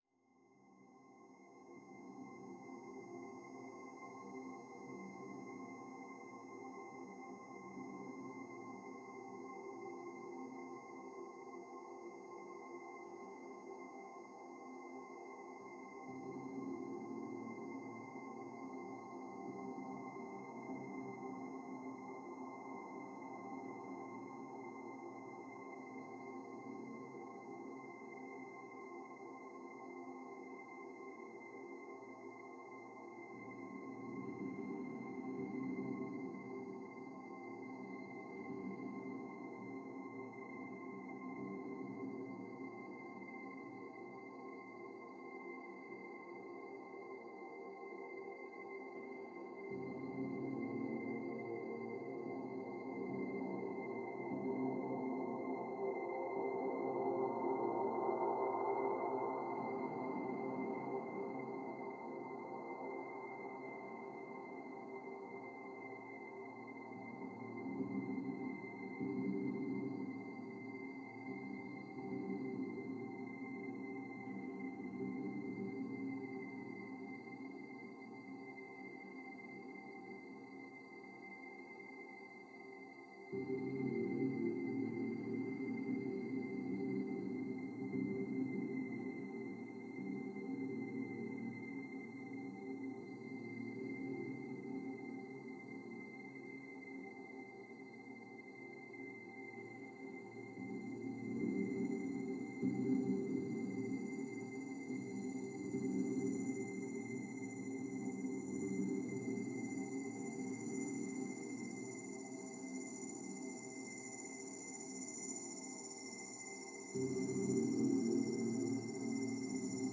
Barely-there micro-minimalism “In 2000